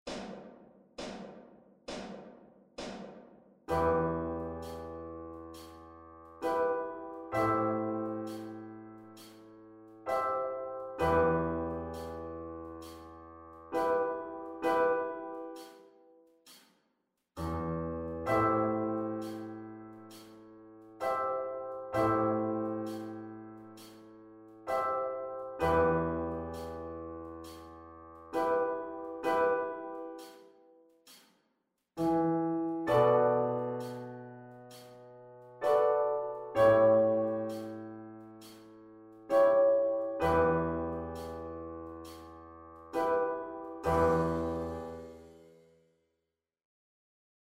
Em pentatonic scale improvising accompaniment (slower track)